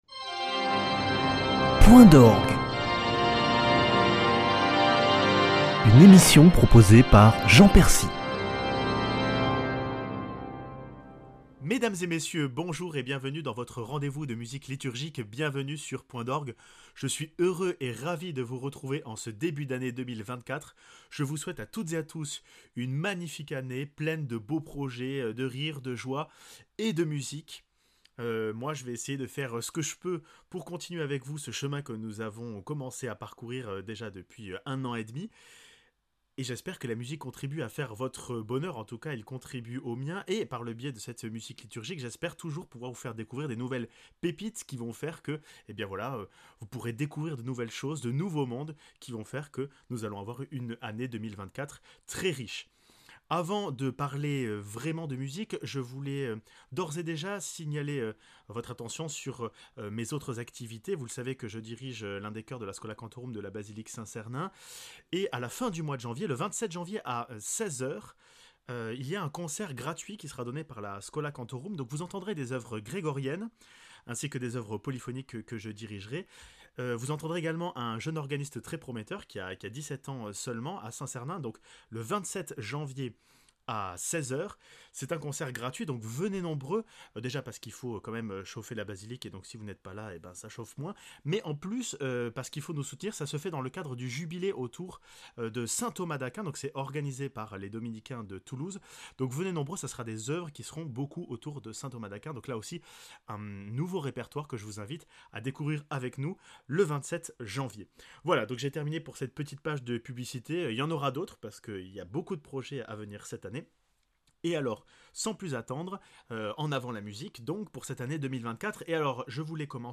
Accueil \ Emissions \ Culture \ Musique \ Point d’orgue \ Bonne année !